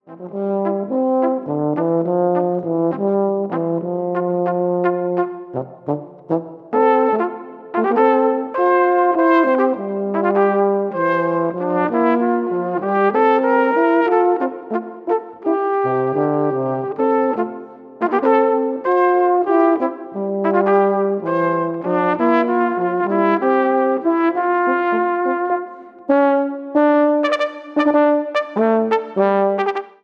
Gattung: für Flügelhorn und Tenorhorn
Besetzung: VOLKSMUSIK Weisenbläser